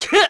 Zafir-Vox_Attack4_kr.wav